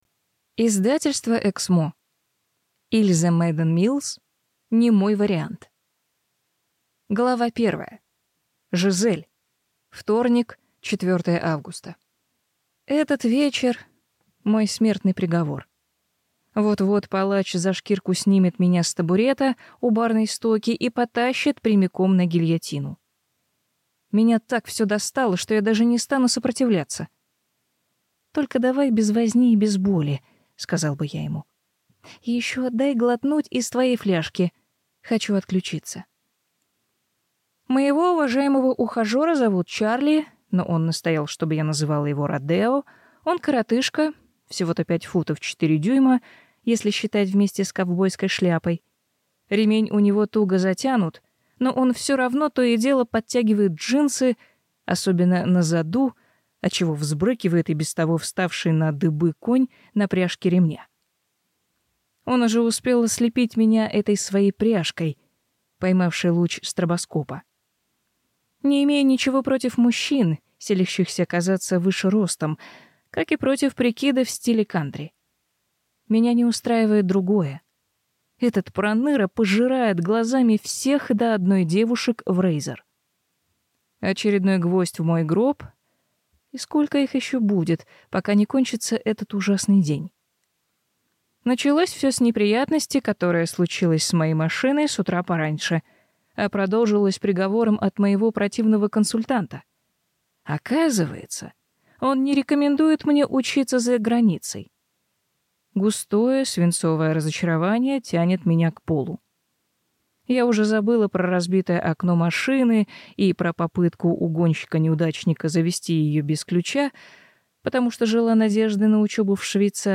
Аудиокнига Не мой вариант | Библиотека аудиокниг
Прослушать и бесплатно скачать фрагмент аудиокниги